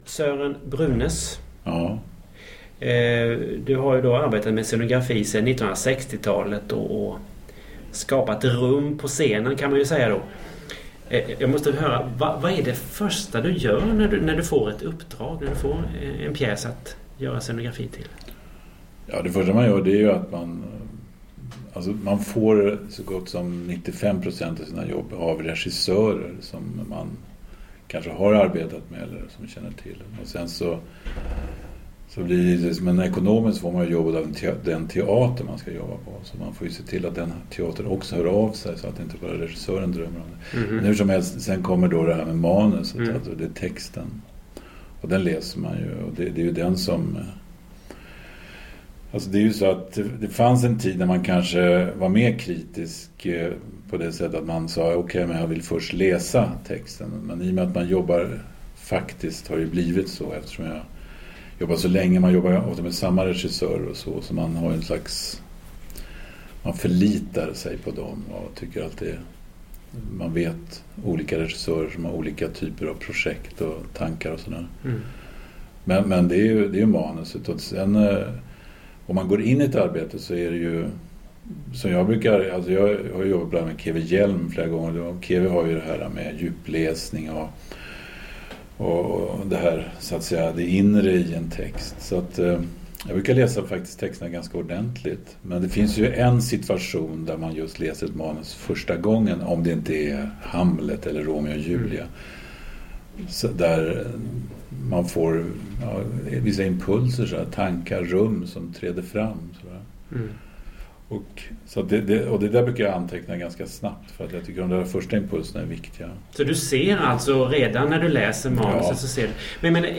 I november 2002 var han på besök i Ronneby, inbjuden av Teaterfixarna, och berättade om sitt arbete från 1960-talet och framåt. Här är hela intervjun och en del av det samtal som han höll med teaterintresserade.